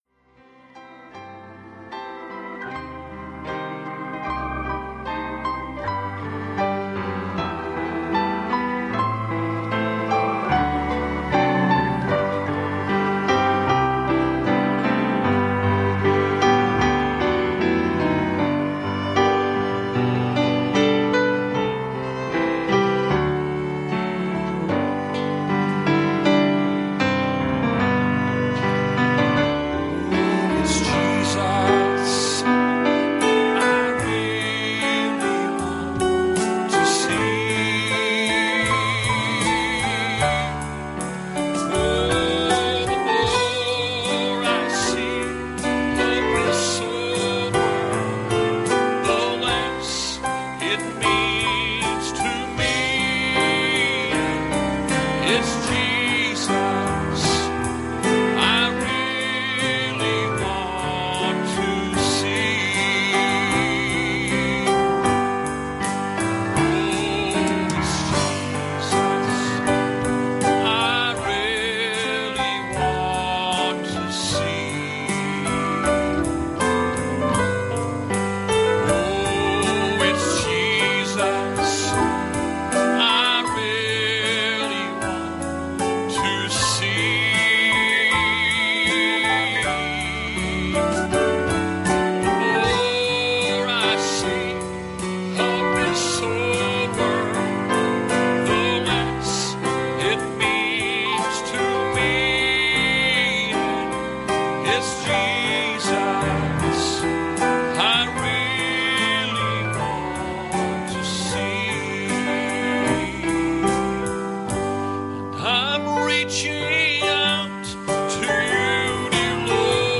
Passage: Isaiah 1:8 Service Type: Wednesday Evening